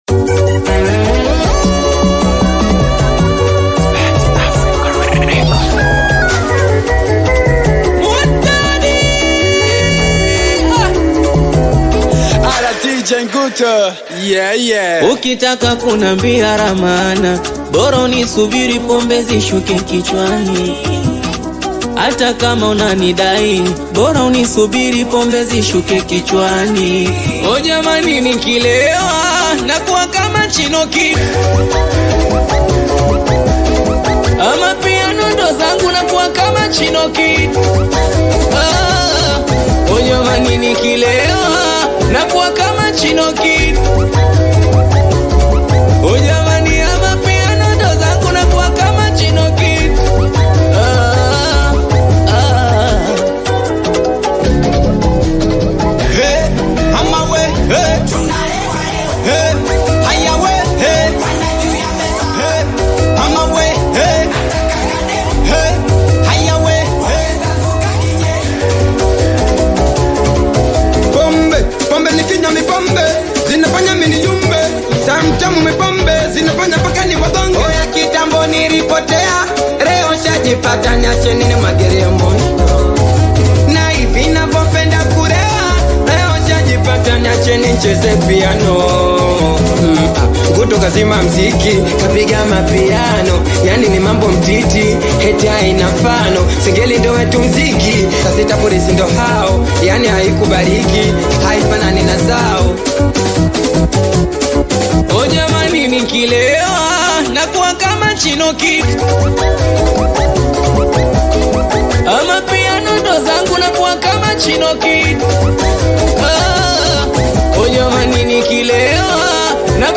Tanzanian Bongo Flava Singeli
Singeli